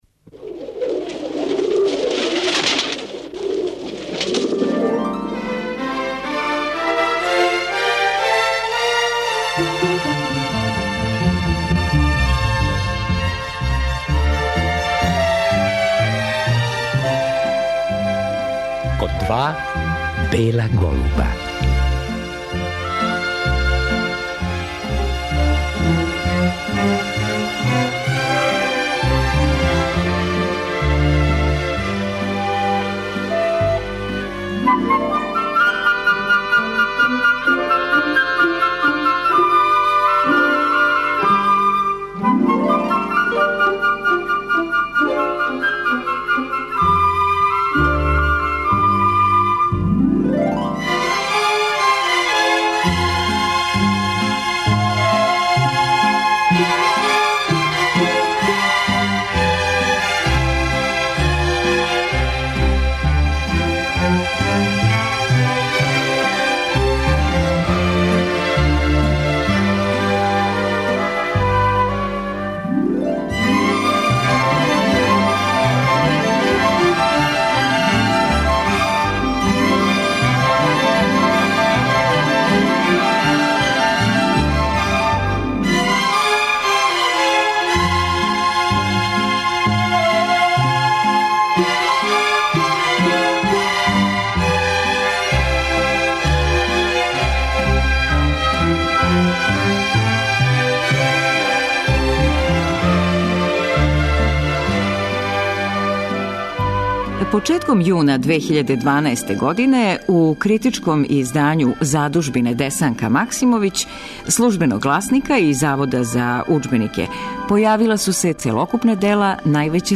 У години у којој се навршава двадесет година од одласка песникиње Десанке Максимовић, чућемо снимак са промоције њених Целокупних дела. Промоција је одржана јуна 2012. године у Ректорату Београдског универзитета.